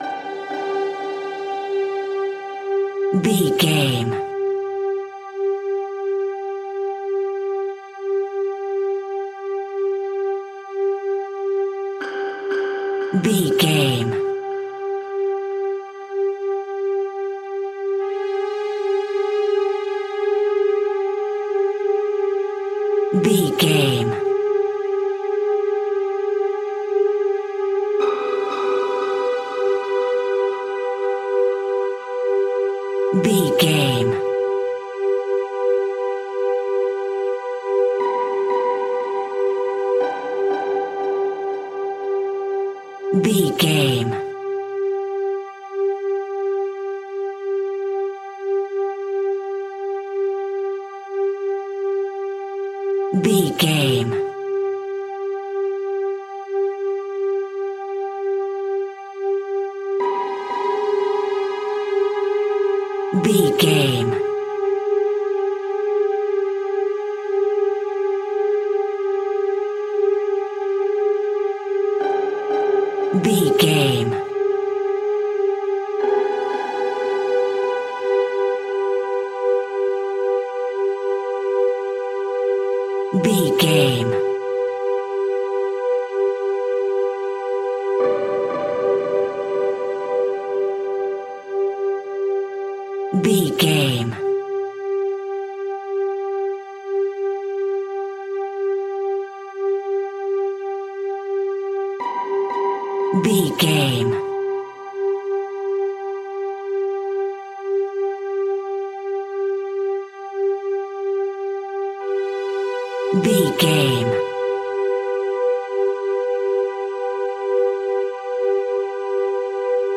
Thriller
Aeolian/Minor
A♭
Slow
tension
ominous
dark
haunting
eerie
synthesizer
strings
Synth Pads
Synth Strings
synth bass